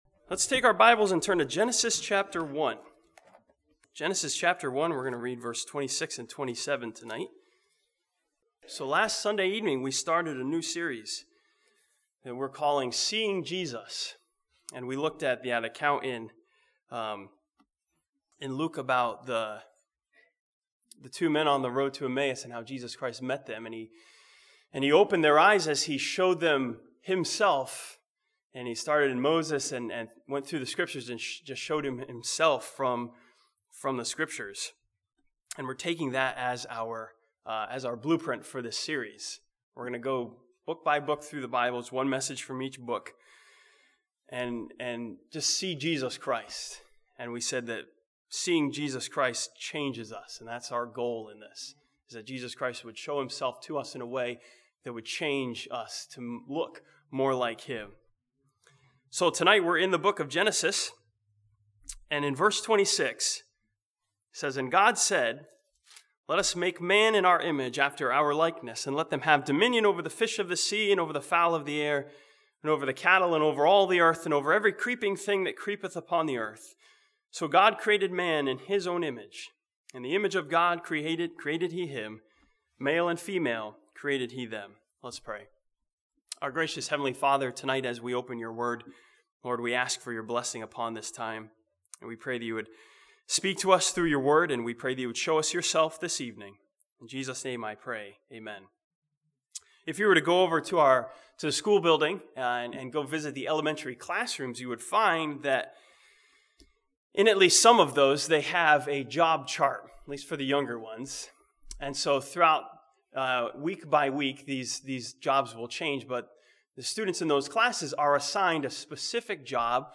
This sermon from Genesis chapter 1 challenges believers to see Jesus as our Creator.